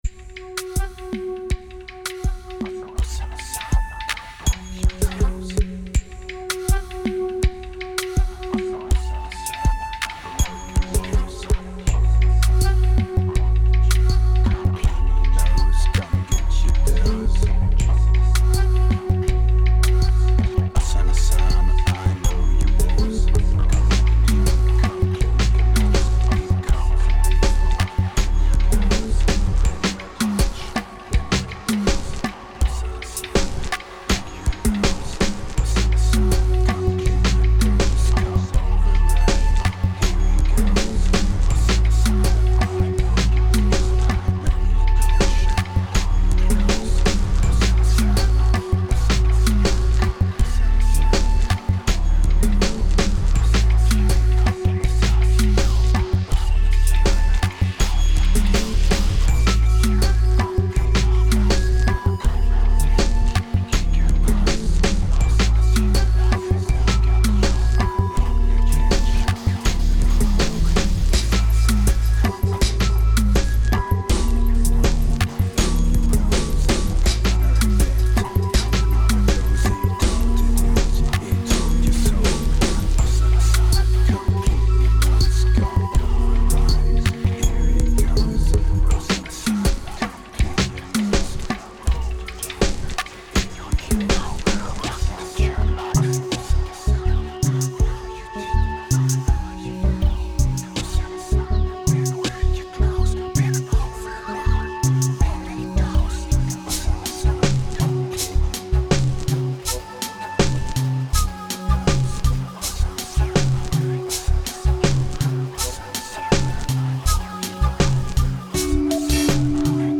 Tight and progressive, it seems to flow out more naturally.
2380📈 - 0%🤔 - 81BPM🔊 - 2008-11-01📅 - -227🌟